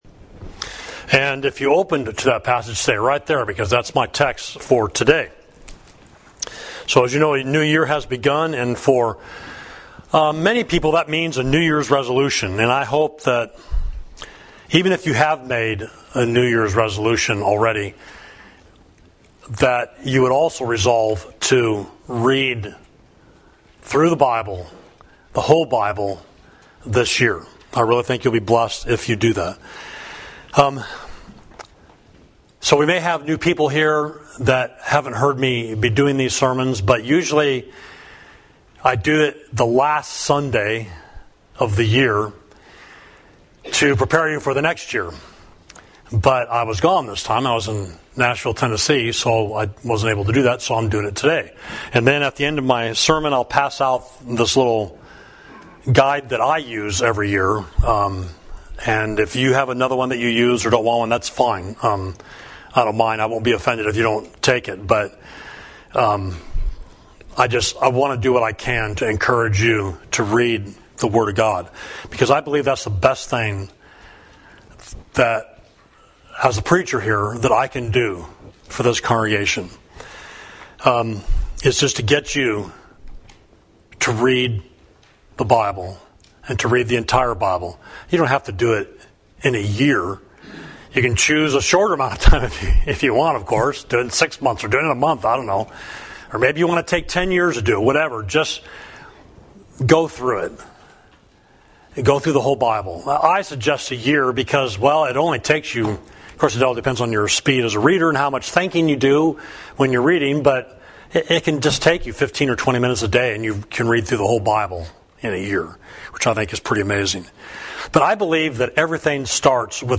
Sermon: The Eighth 8 Reasons for Reading the Bible